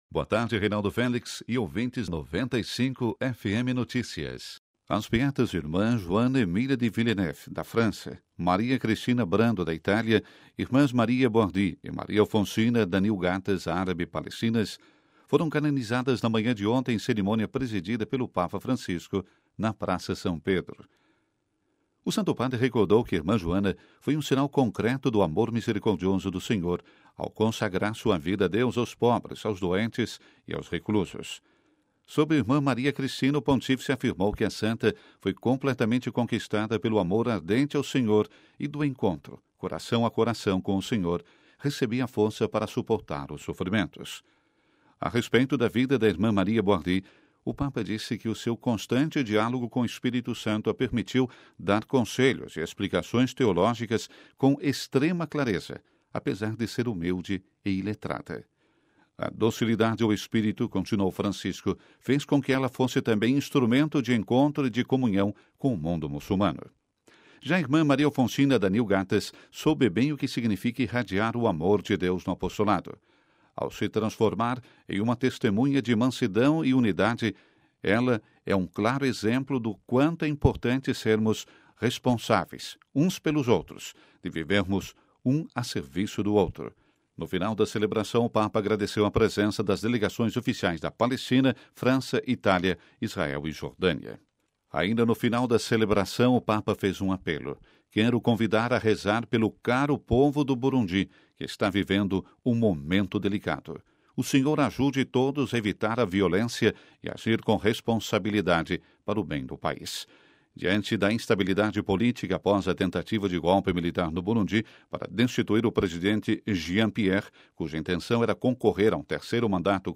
Boletim da Rádio Vaticano